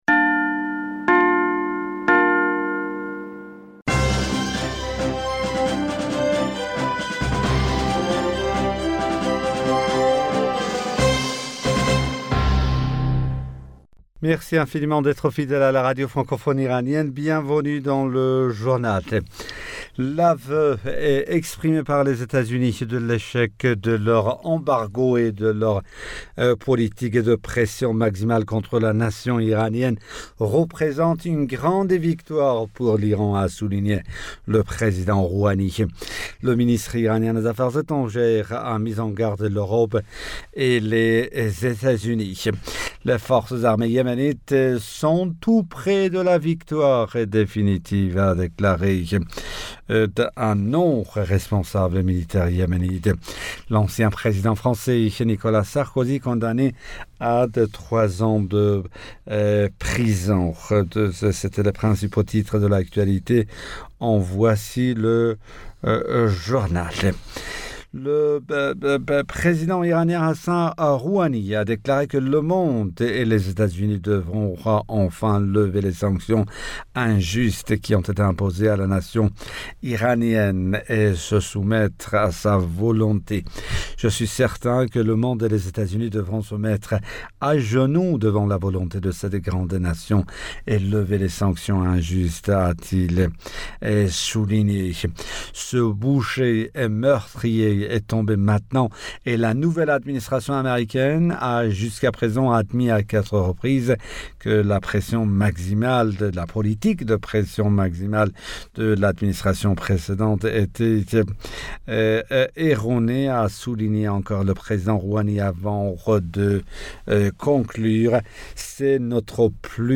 Bulletin d'informationd u 02 Mars 2021